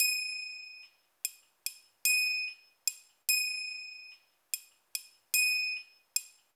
BOL FING CYM.wav